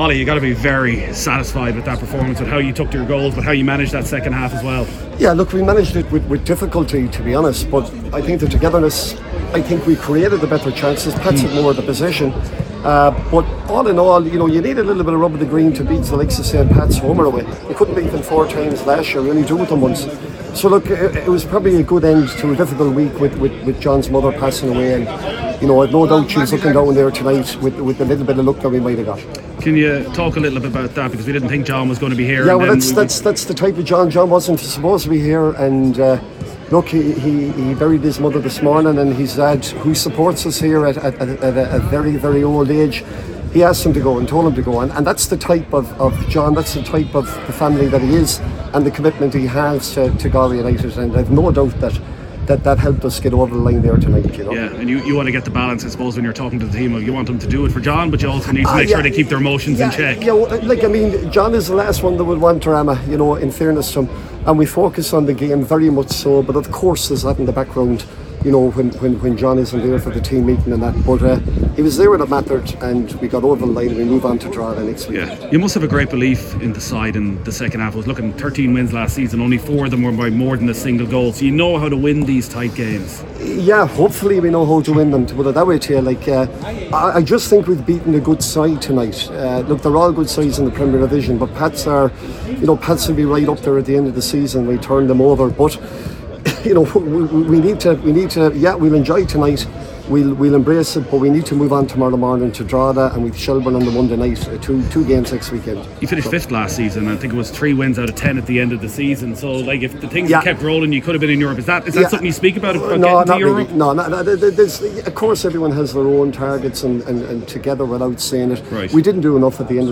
After the game,